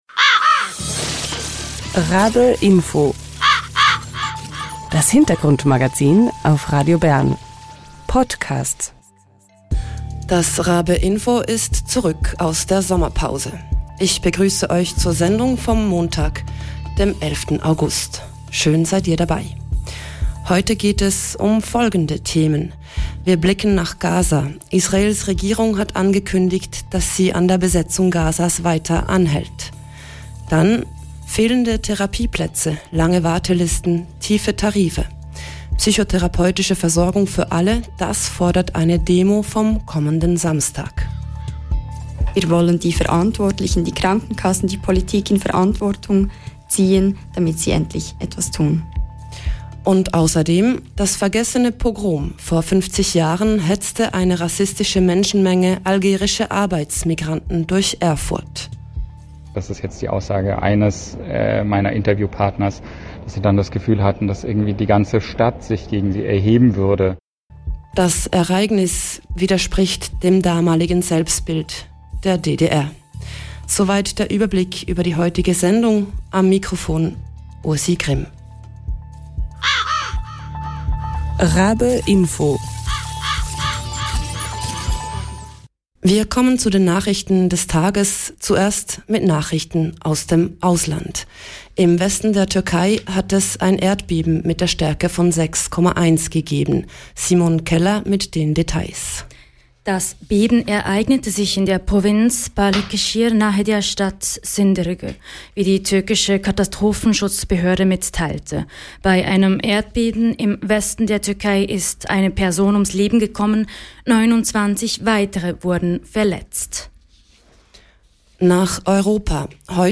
In der heutigen Sendung blicken wir nach Gaza, wo Israels Besetzung anhält. Dann hören wir im Talk die Psychologin und ehemalige Stadtratspräsidentin Valentina Achermann über die Krise in der psychischen Gesundheitsversorgung.